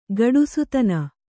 ♪ gaḍusutana